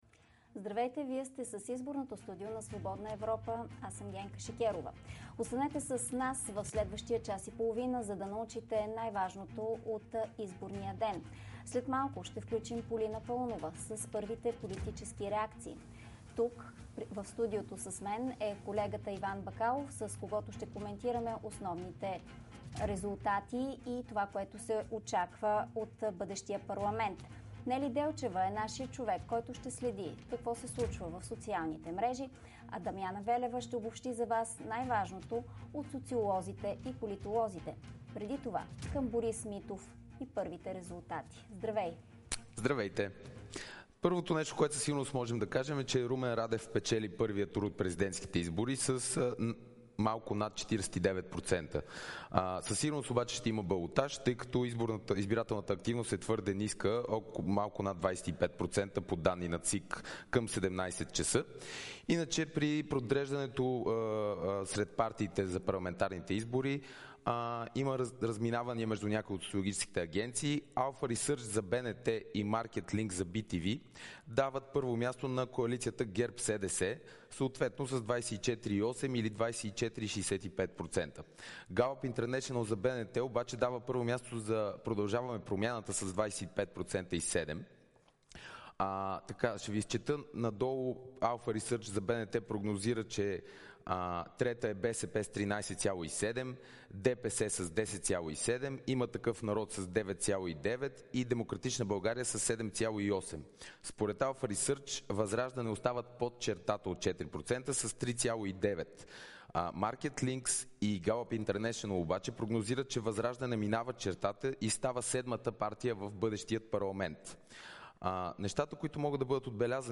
Какви са първите резултати след края на изборния ден. Какви са реакциите на големите и по-малките победители. Слушайте изборното студио на Свободна Европа.